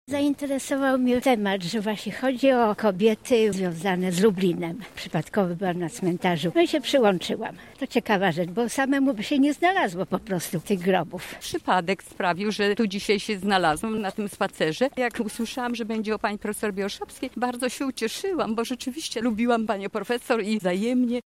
Jak mówią sami uczestnicy, przyszli z zaciekawienia lub przy okazji.